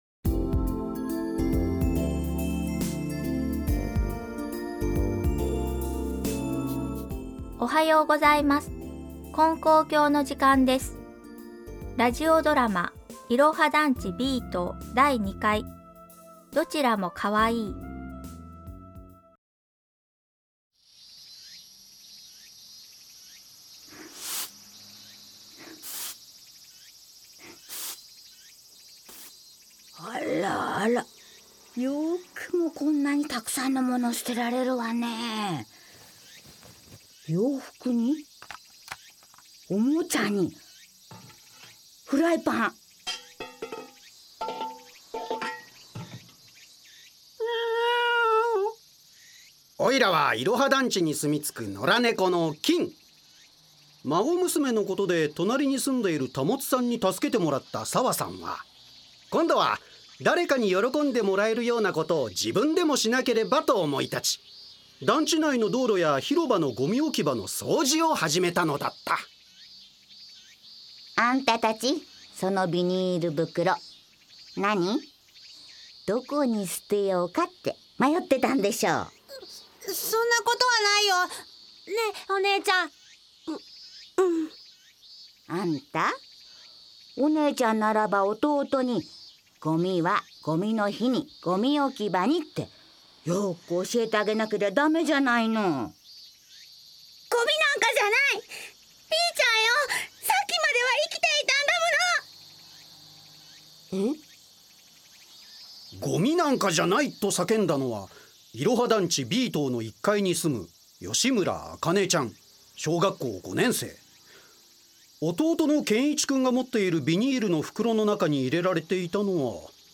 ●ラジオドラマ「いろは団地Ｂ棟」